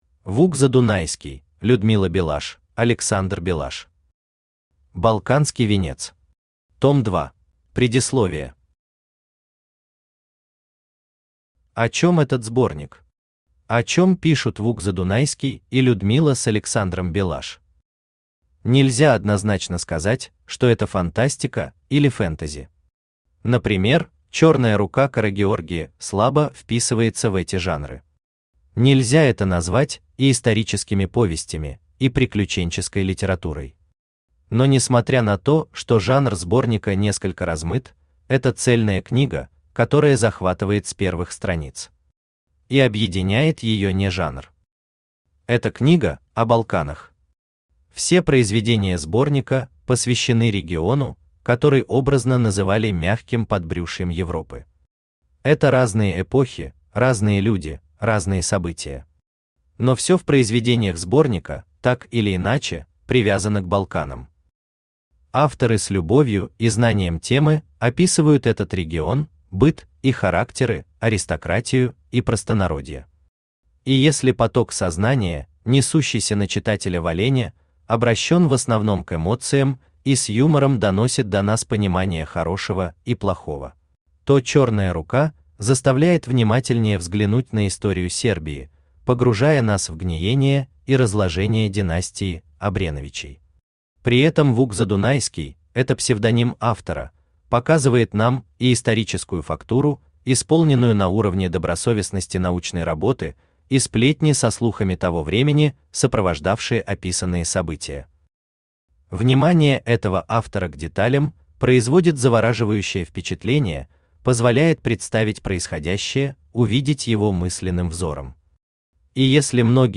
Аудиокнига Балканский венец. Том 2 | Библиотека аудиокниг
Том 2 Автор Вук Задунайский Читает аудиокнигу Авточтец ЛитРес.